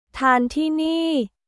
ターン・ティーニー